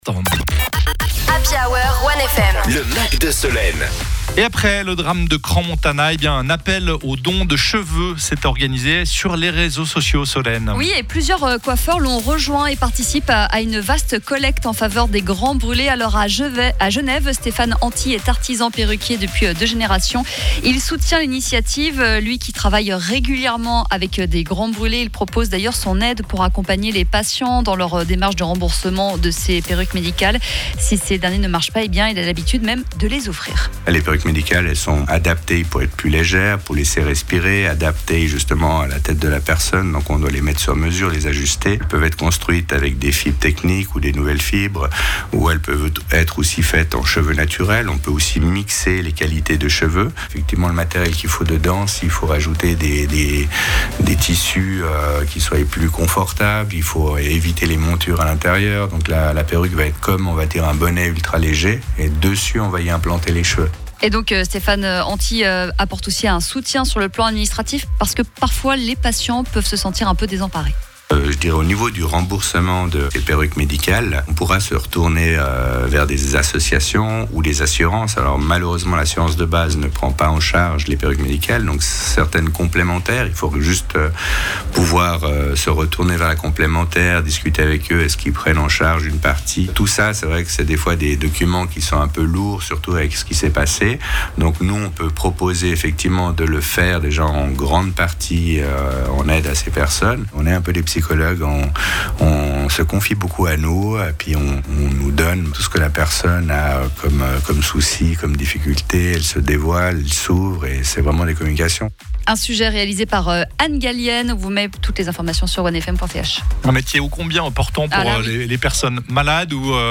Un perruquier genevois nous explique les spécificités des perruques médicales.